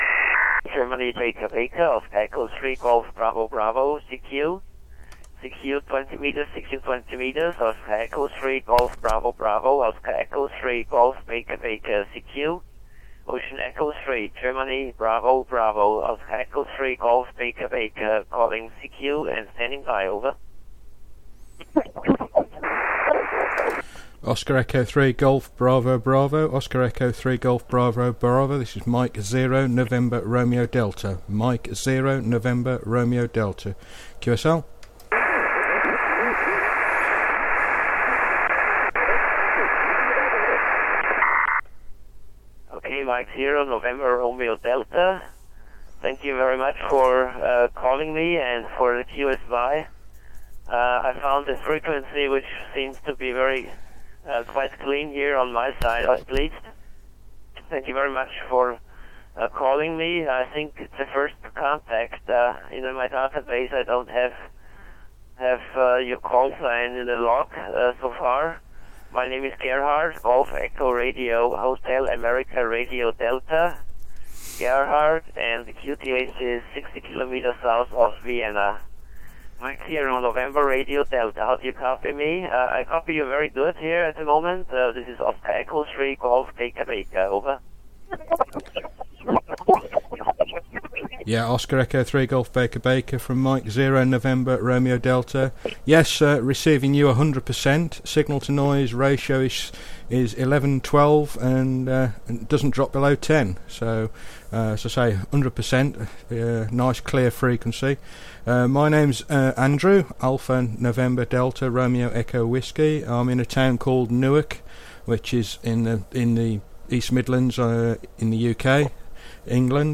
FreeDV QSO recorded 13:09UTC 07-Dec-2014